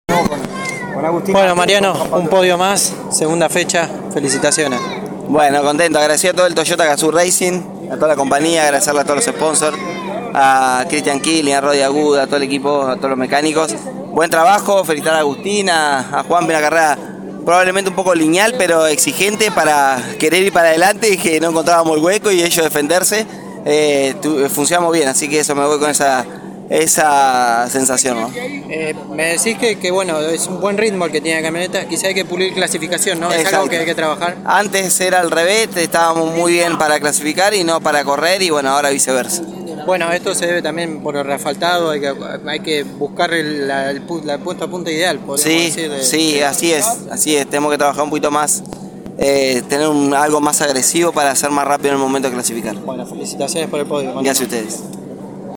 El entrerriano pasó por los micrófonos de Pole Position y habló del segundo podio consecutivo conseguido dentro de las TC Pickup en La Plata.